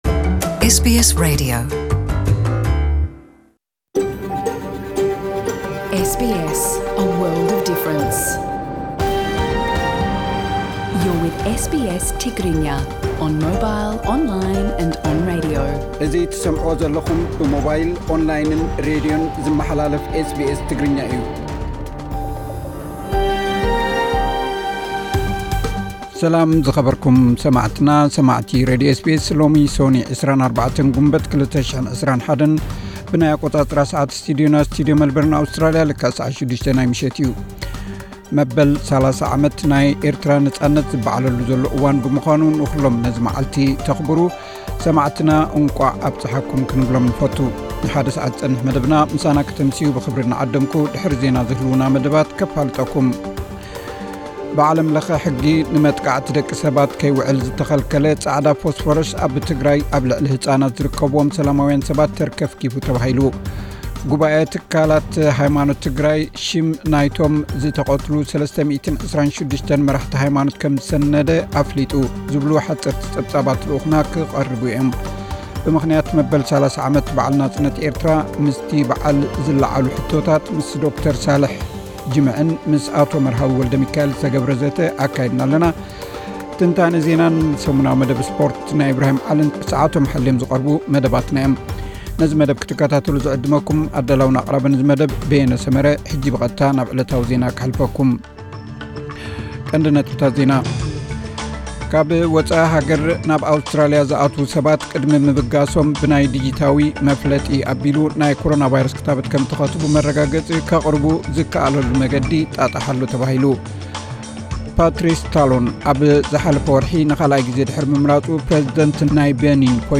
መበል 30 ዓመት ነጻነት ኤርትራ፥ ኣብ ዉሽጢ ኤርትራን ወጻእን ብኤርትራዊያን ይበዓል ኣሎ። (ካብ ዜና)